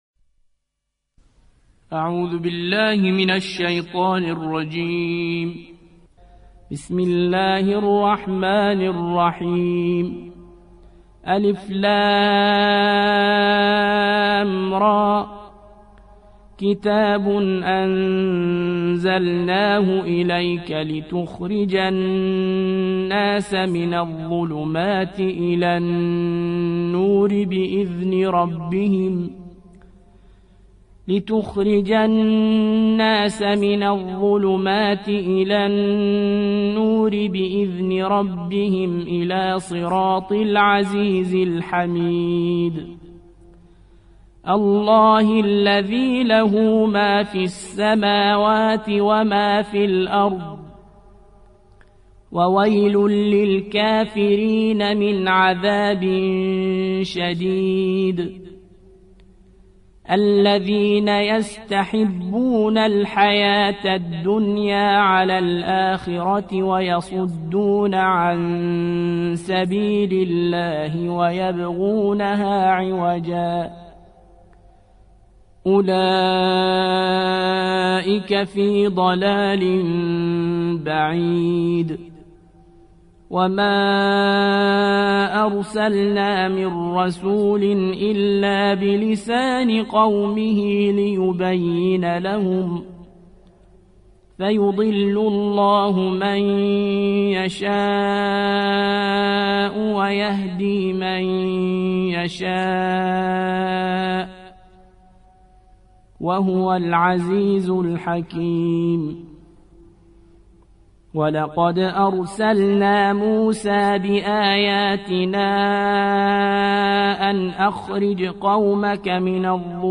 14. سورة إبراهيم / القارئ